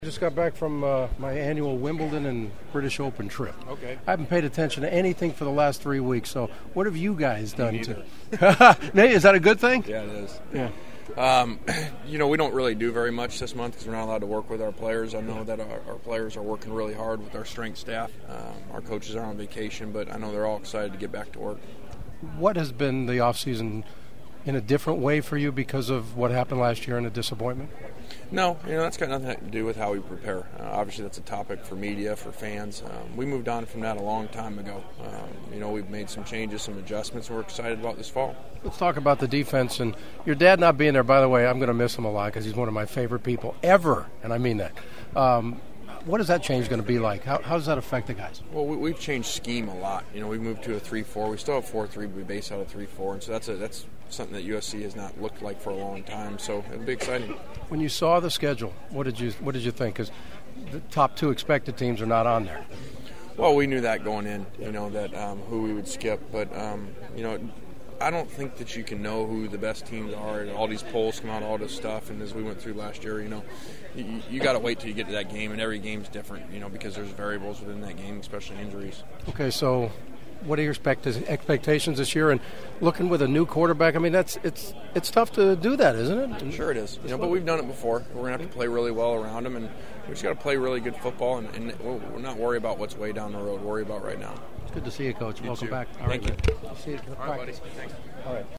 Pac 12 football media at Sony Studios
Coach Kiffin wasn’t in the mood to give me much real insight
USC coach Lane Kiffin: